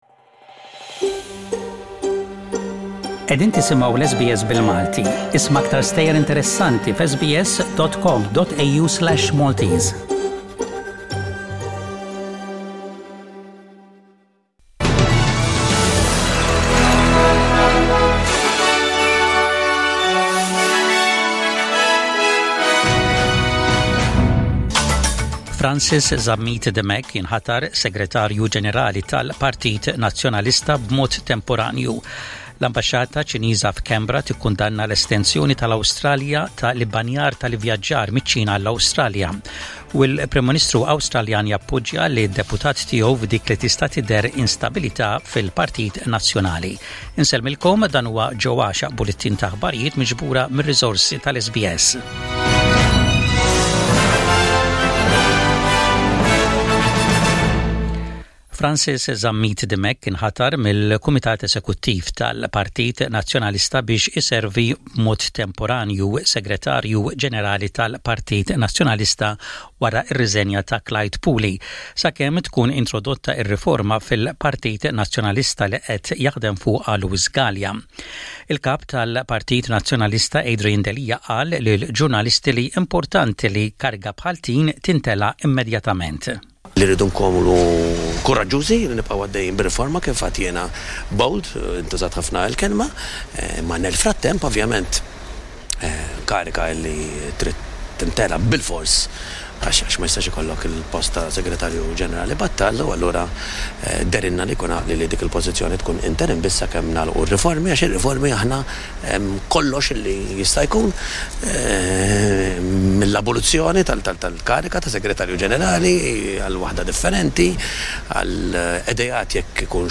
SBS Radio | Maltese News: 14/02/20